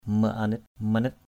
/mə-a-nɪt/ (cv.) manit mn{T /mə-nɪt/ (t.) dễ thương. lovely. ban asit maong manit biak bN ax{T _m” mn{T b`K thằng nhỏ trông dễ thương thật.